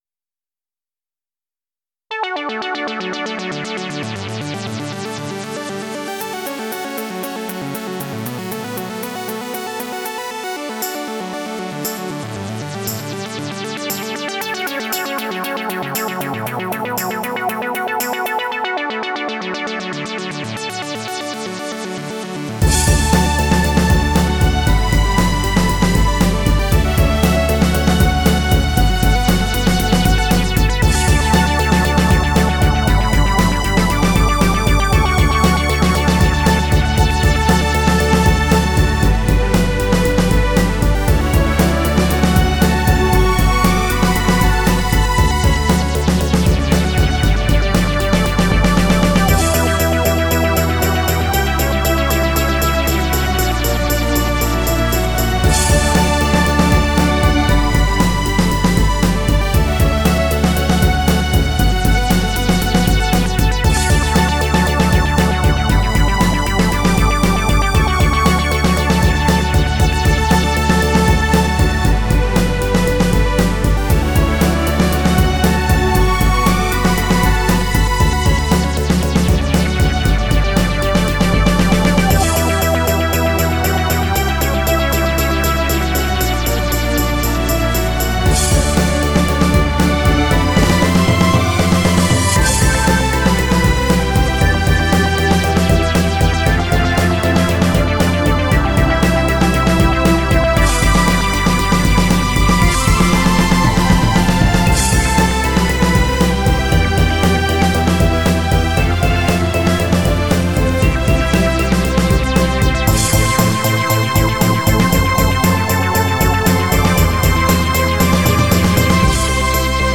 I slapped together a cheeseball MIDI version of
Nevermind the 303 arpeggios, the straight 8ths bass, the drum patterns, and the slightly different form.
However, I do think that the tempo is as fast as the song can go--117 BPMs.